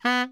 Index of /90_sSampleCDs/Giga Samples Collection/Sax/BARITONE DBL
BARI  FF B 2.wav